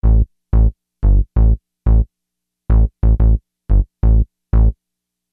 Hiphop music bass loops 4
Hiphop music bass loop - 90bpm 65